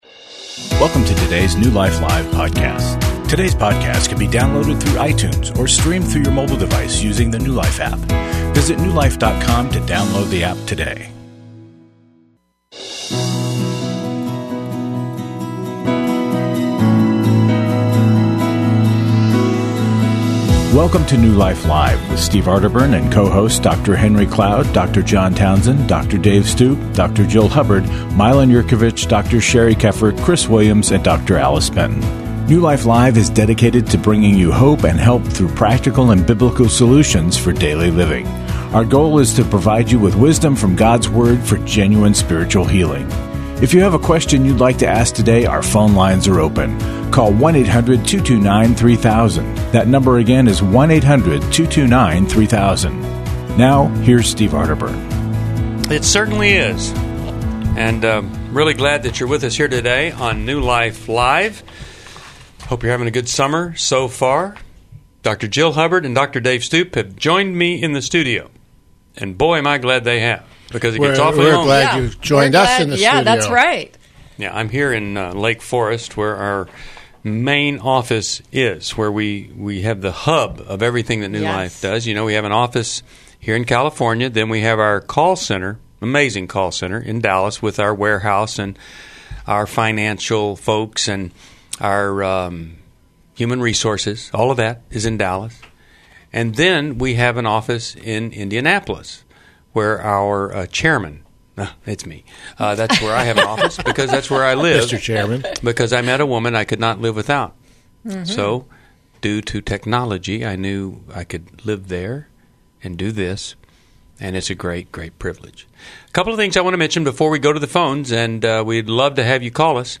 Caller Questions: My wife was unfaithful and I have been angry and distant for years; what can I do?